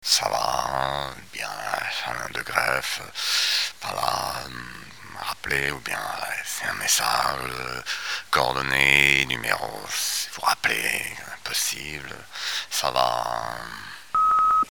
Messages d'imitations 1: